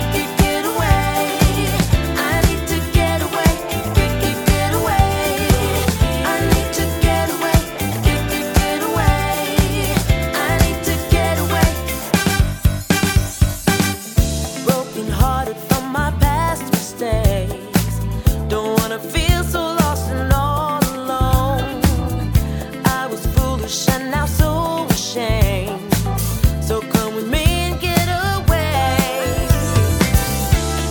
chant...